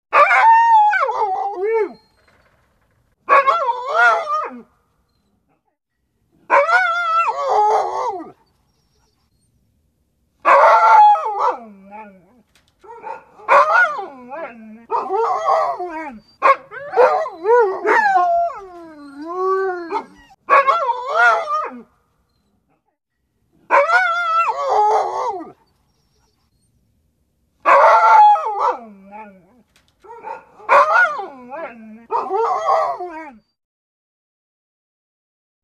11 Вой собаки MP3 / 558 Кб [
11-unhappy_dog.mp3